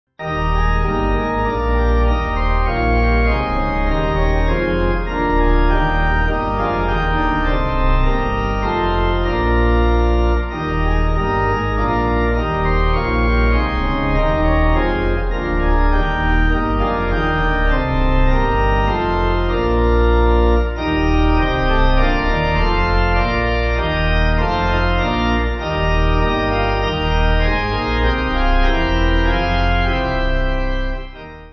(CM)   3/Eb